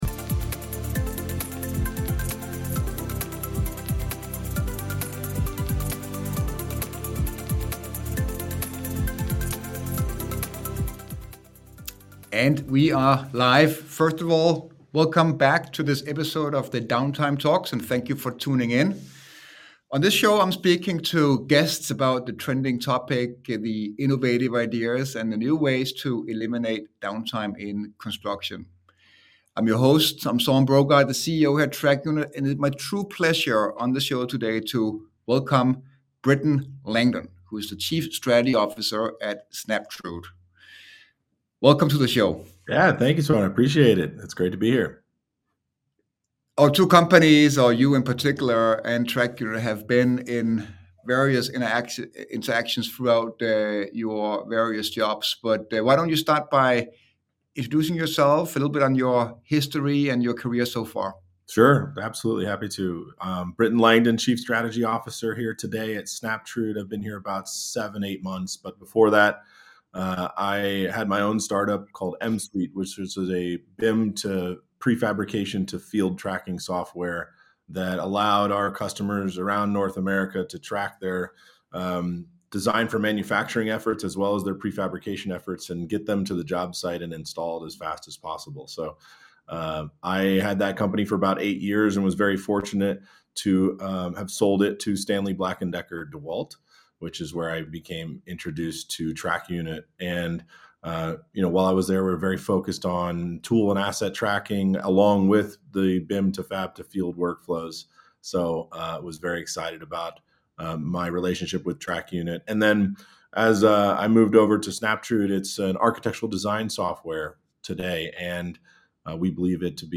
Join a conversation on why cloud technology helps construction become more collaborative and limiting factors for new ways of working on construction.